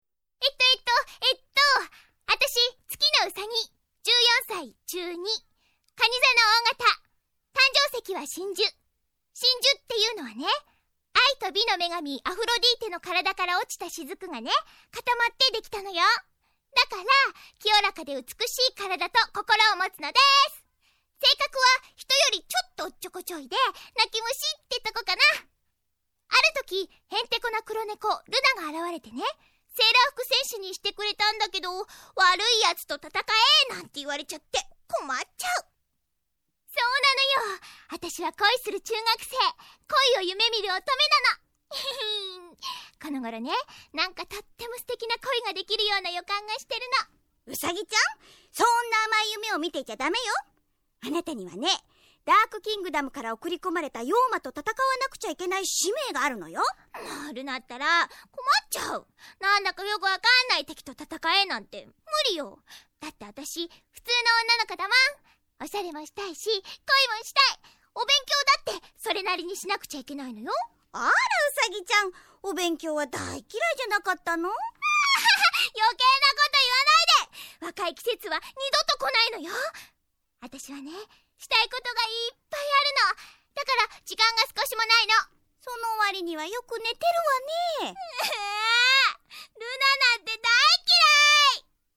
미소녀전사 세일러문 ~사랑은 어디에 있나요~에 수록된 우사기와 루나(때로는 느끼한 턱시도 가면님[;;])의 대화를 듣고 번역한 것입니다 :)